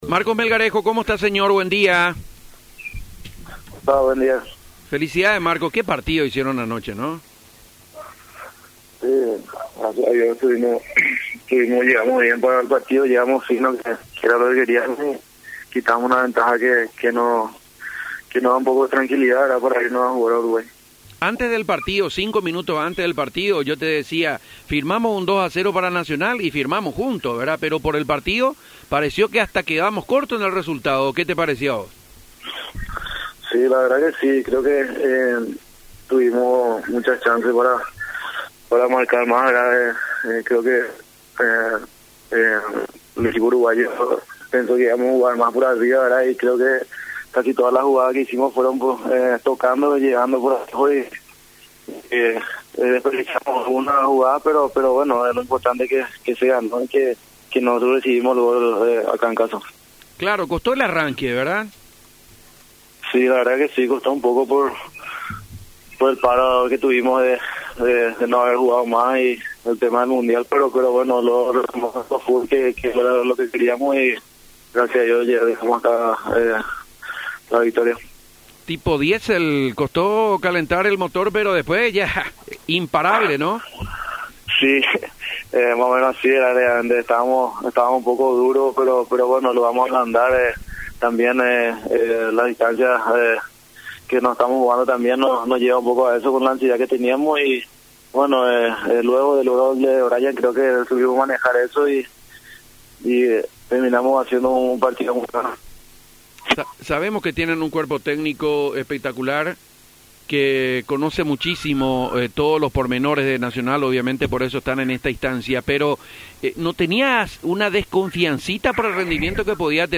En el programa de hoy hablaron dos referentes del equipo nacionalófilo: